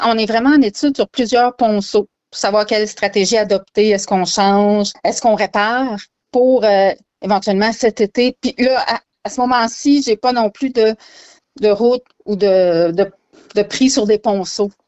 La mairesse, Sandra St-Amour, a toutefois précisé qu’il était encore trop tôt pour en connaître les conclusions.